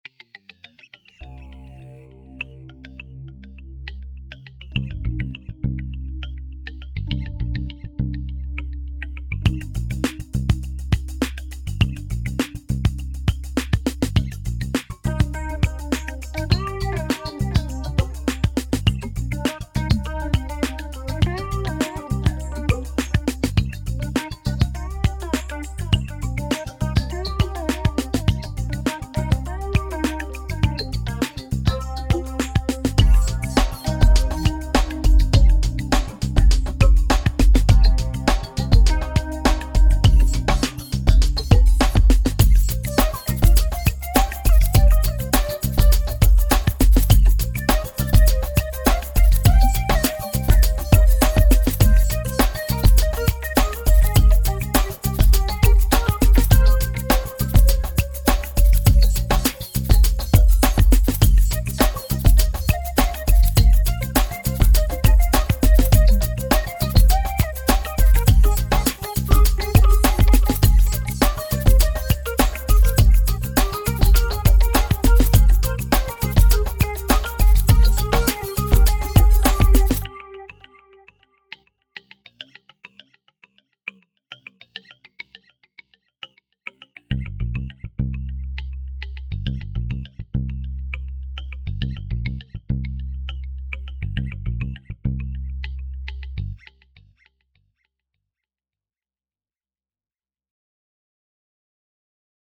dance/electronic
Ambient
Pop